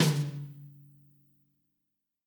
tom1.ogg